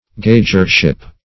Gauger-ship \Gau"ger-ship\, n. The office of a gauger.